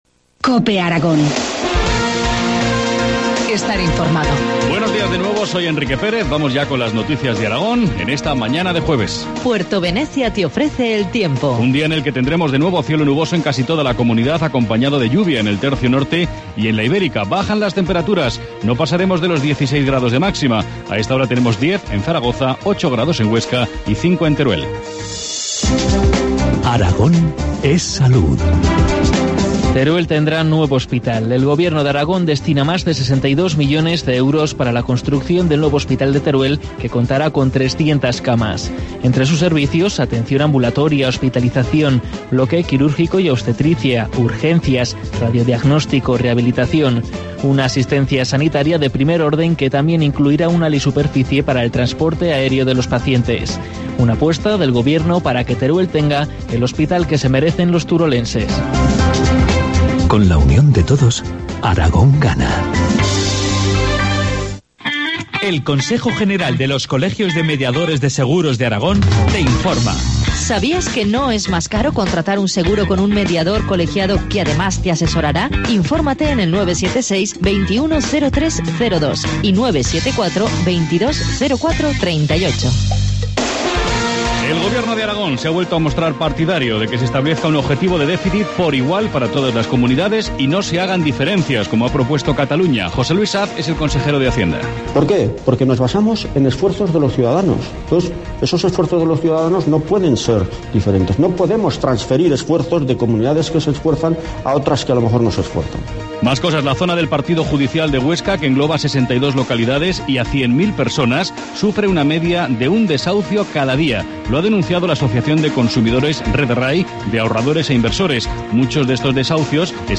Informativo matinal, jueves 4 de abril, 8.25 horas